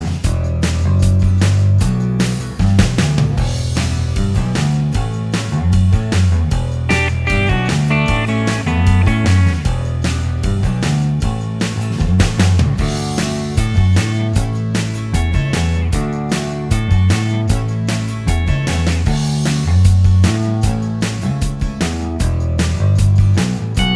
Minus Guitars Pop (1980s) 3:50 Buy £1.50